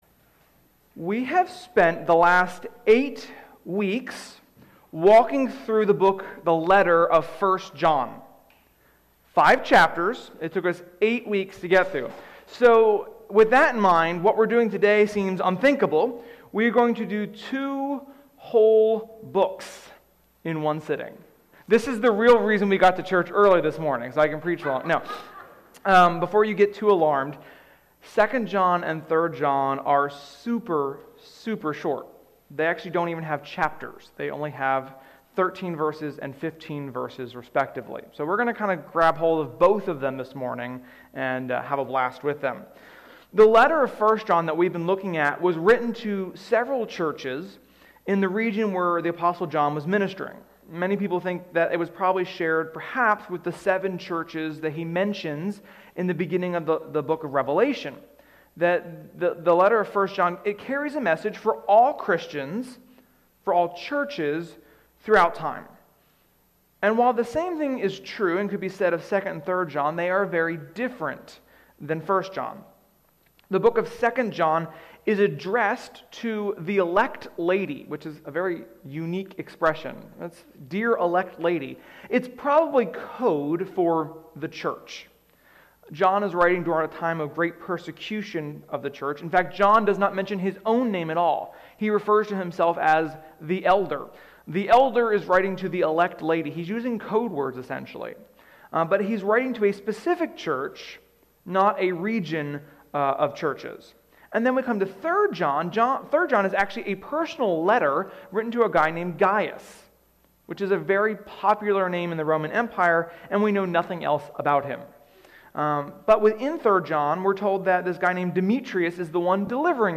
Sermon-5.30.21.mp3